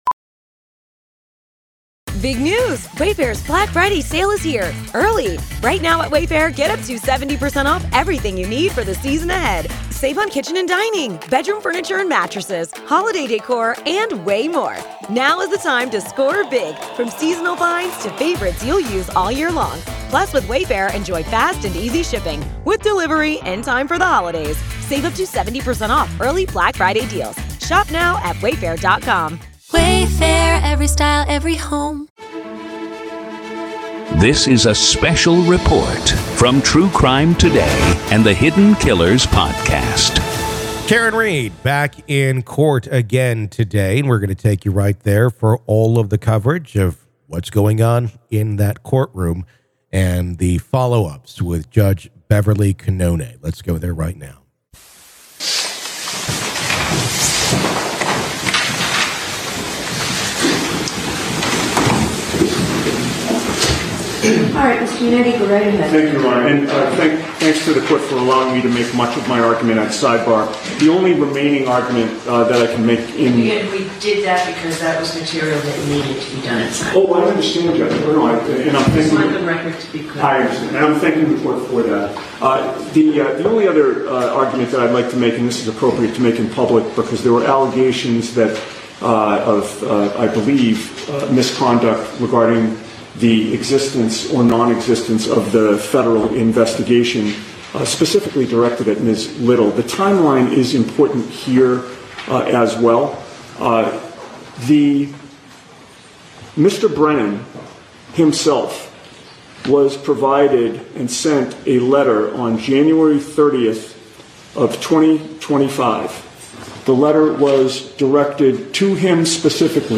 MA v. Karen Read Murder Retrial - Motions Hearing Day 1 PART 3